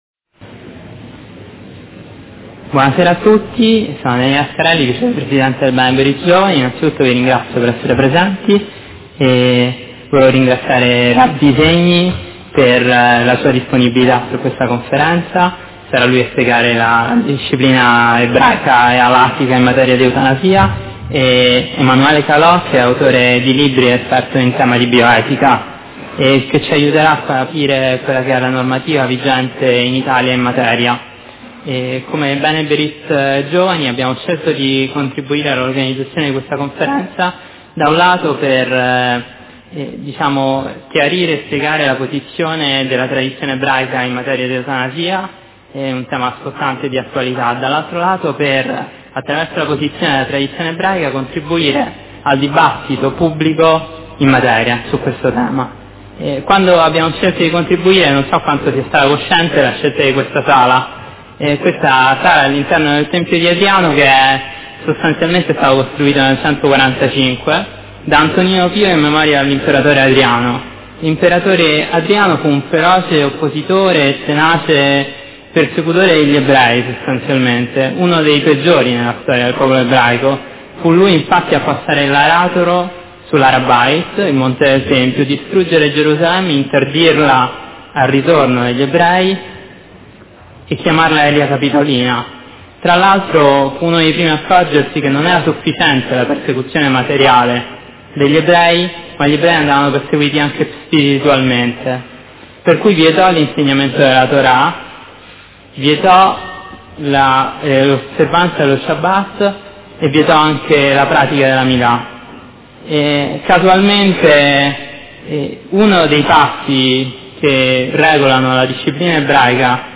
L�audio della conferenza (.mp3)